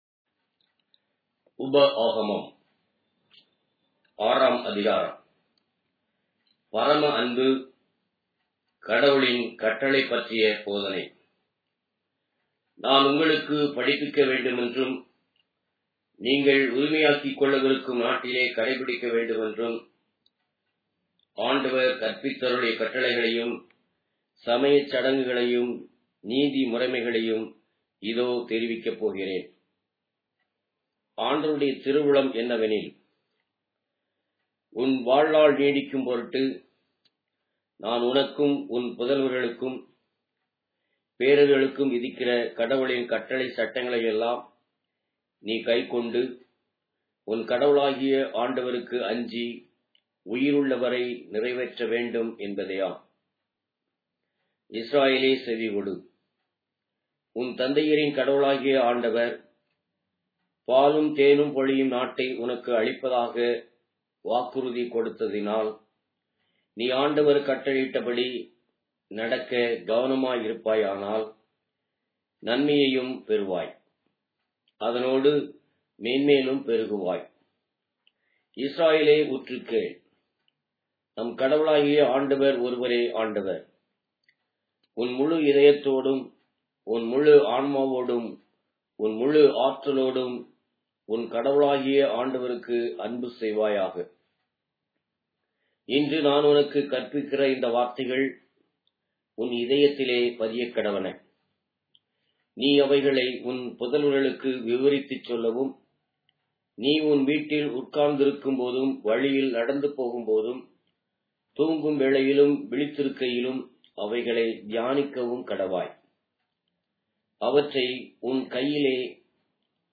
Tamil Audio Bible - Deuteronomy 1 in Rcta bible version